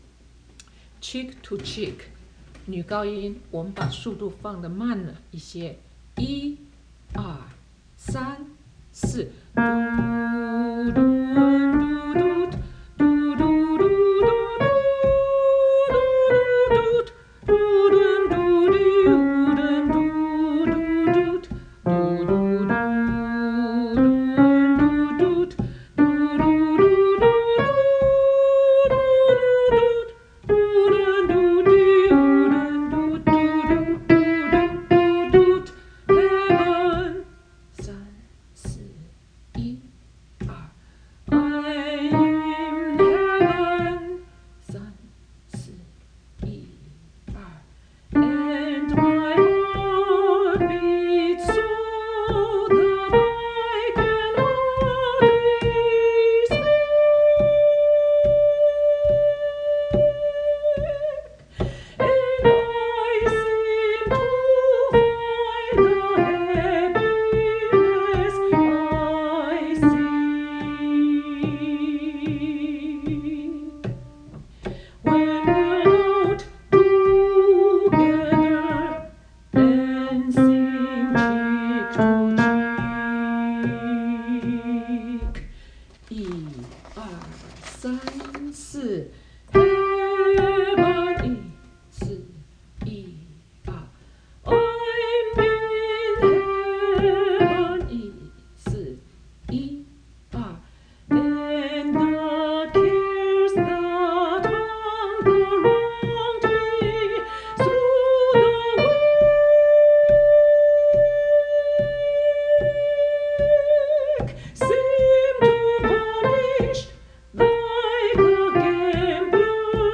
Cheek to Cheek Soprano.mp3